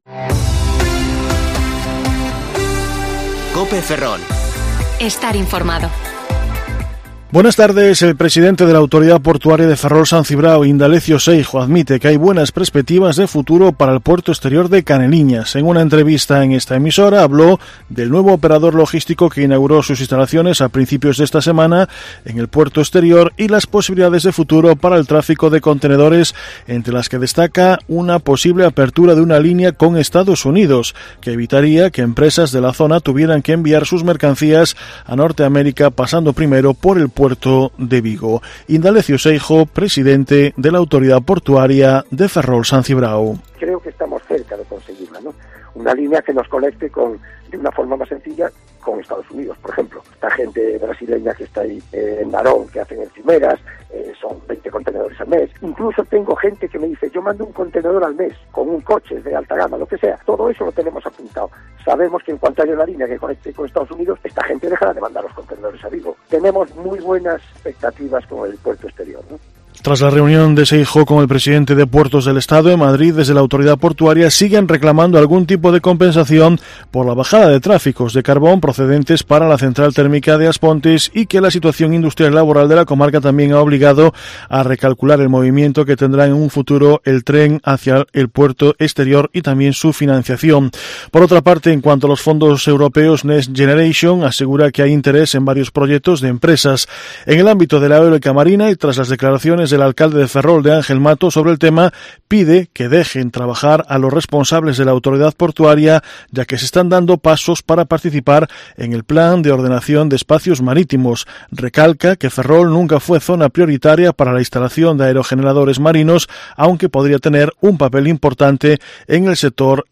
Informativo Mediodía COPE Ferrol 14/5/2021 (De 14,20 a 14,30 horas)